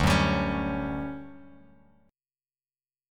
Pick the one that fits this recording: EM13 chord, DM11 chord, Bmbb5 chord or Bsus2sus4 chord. DM11 chord